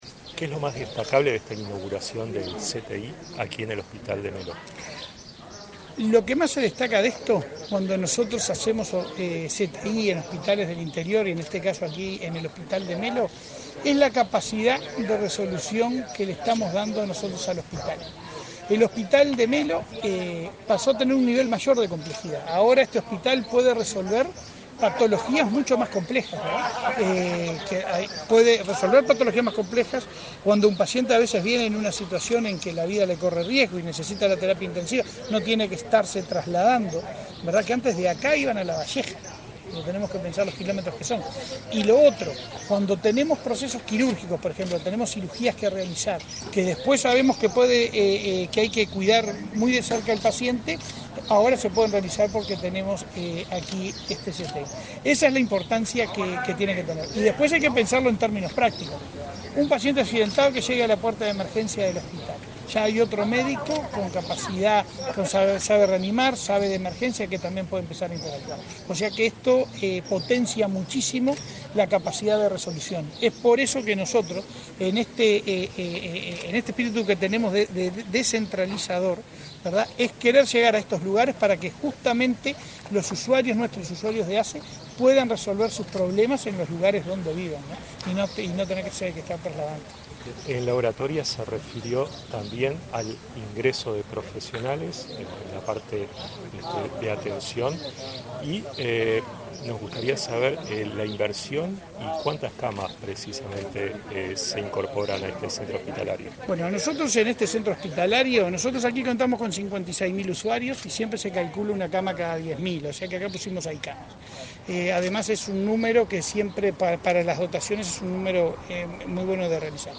Entrevista al presidente de ASSE, Leonardo Cipriani
Entrevista al presidente de ASSE, Leonardo Cipriani 22/12/2023 Compartir Facebook X Copiar enlace WhatsApp LinkedIn Tras participar en la inauguración de un centro de tratamiento intensivo en el hospital de Melo, este 22 de diciembre, el presidente de ASSE, Leonardo Cipriani, realizó declaraciones a Comunicación Presidencial.